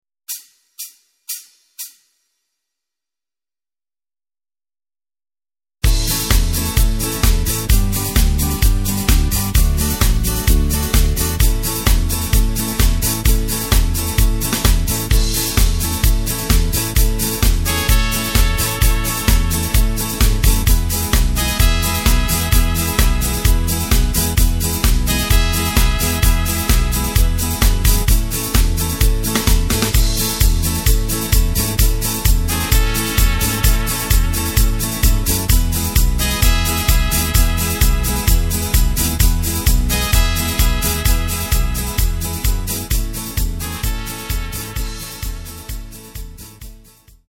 Takt: 4/4 Tempo: 120.00 Tonart: A
Schlager im Mexico-Style
mp3 Playback Demo